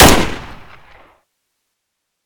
ak74_shoot.ogg